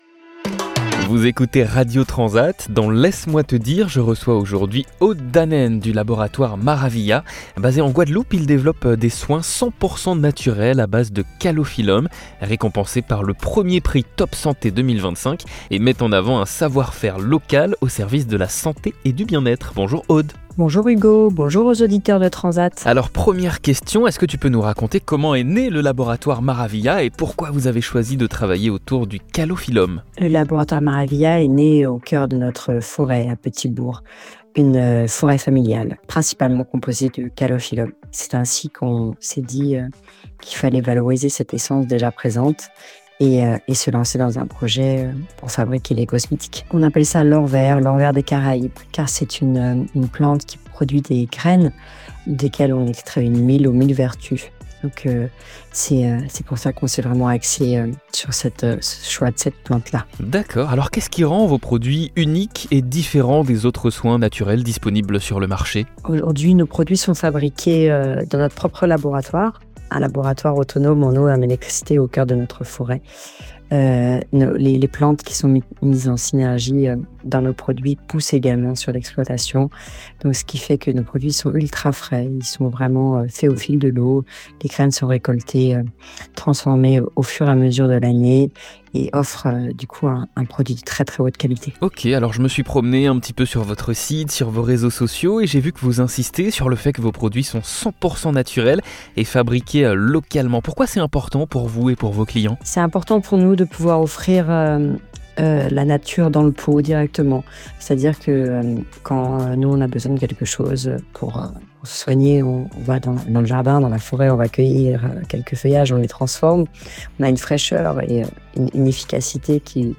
Dans cette interview